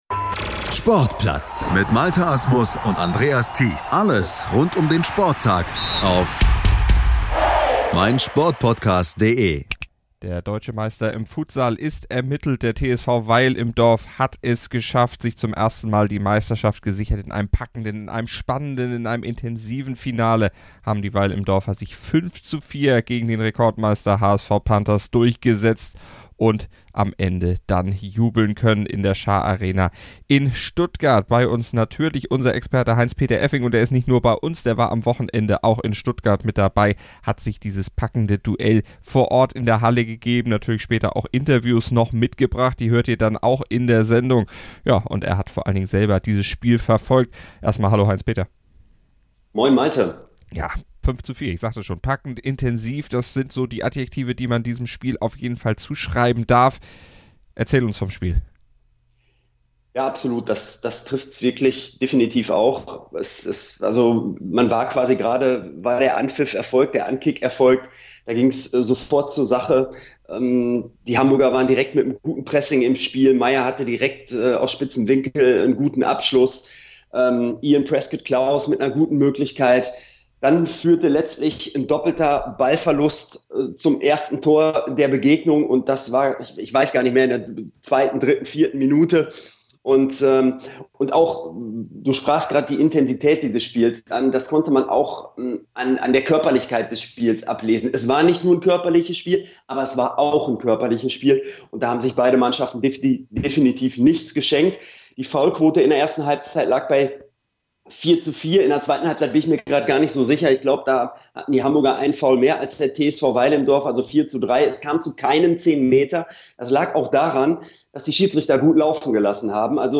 Und sie sprachen mit den Siegern und Besiegten